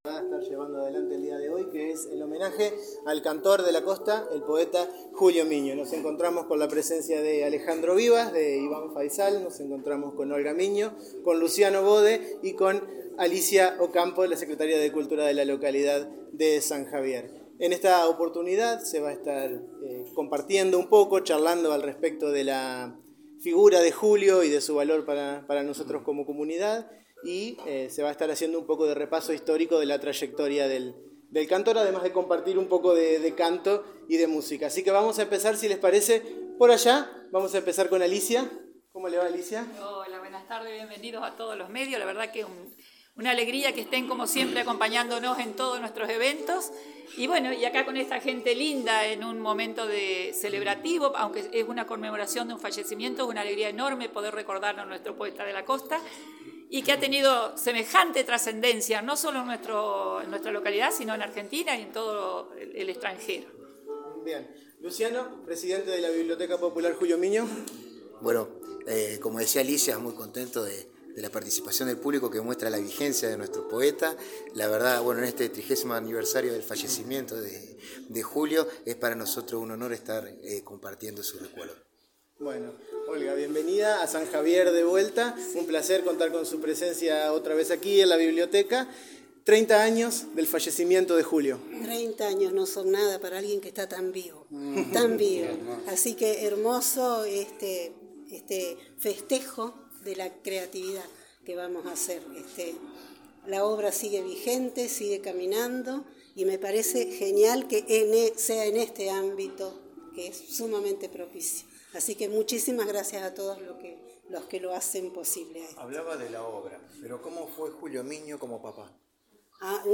La ceremonia tuvo lugar desde las 19 horas en la Biblioteca Popular de San Javier.